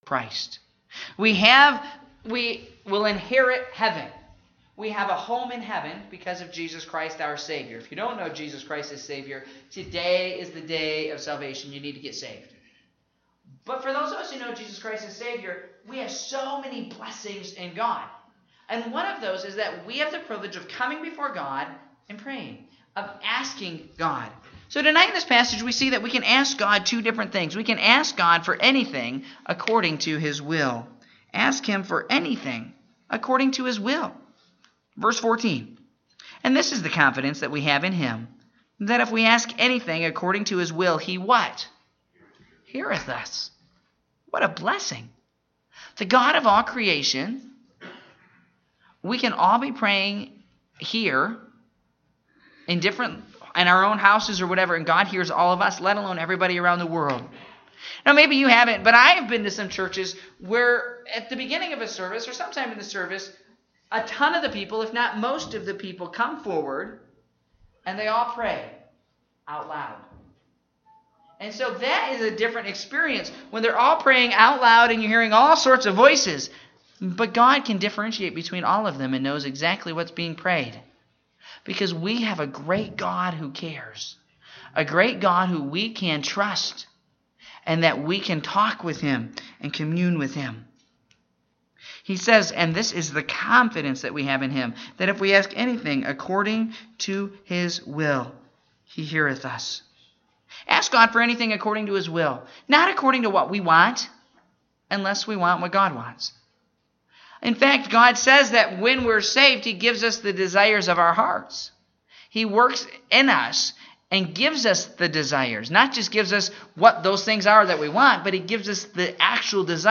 Evening Service (10/22/2017)